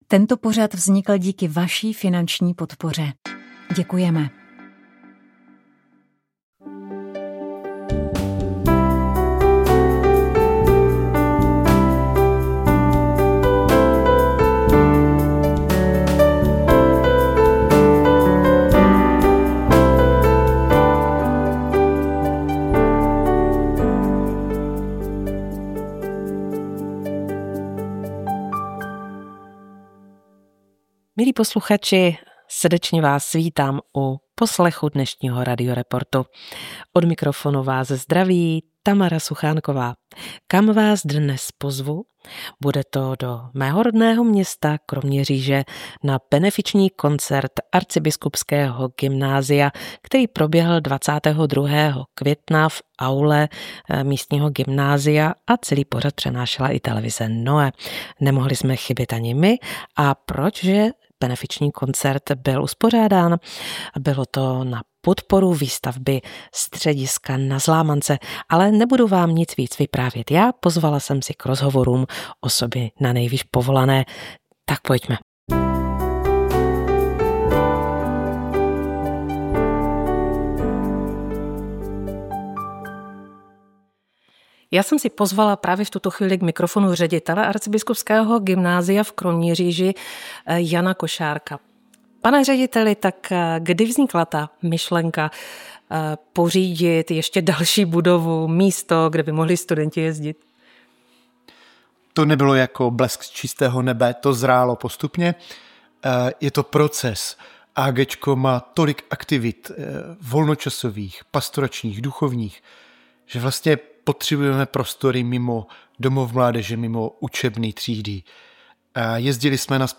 Diecézní centrum života mládeže Vesmír v Deštném v Orlických horách v říjnu letošního roku oslavilo 30 let své existence. Proto jsme k této příležitosti natočili přímo na místě pořad – poslouchejte 16. listopadu 2022 ve 20.15.